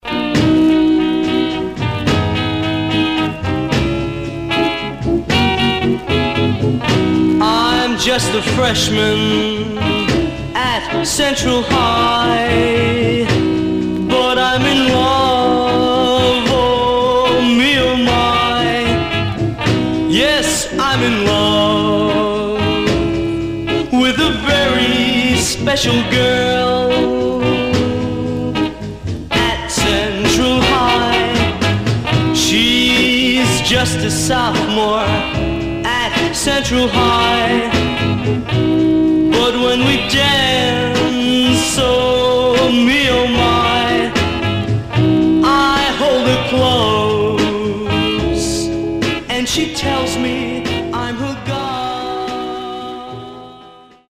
Mono
Teen